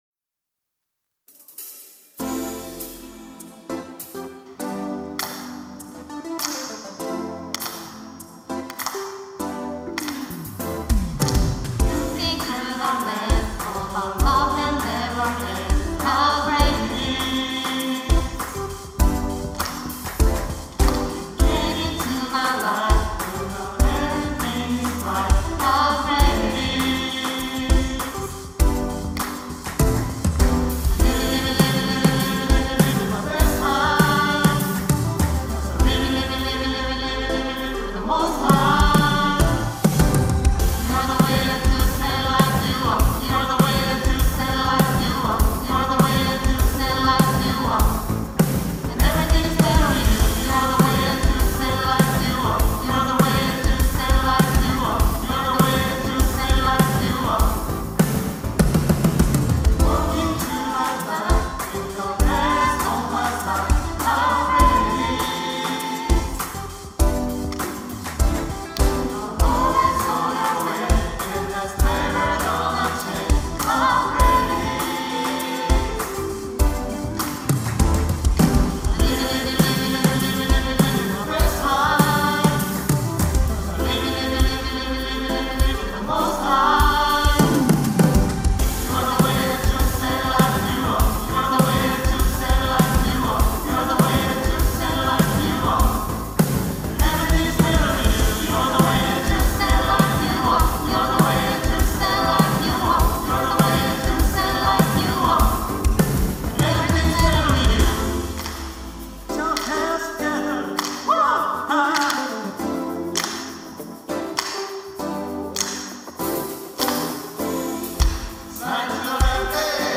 특송과 특주 - Way Truth Life
청년부 윕프레이즈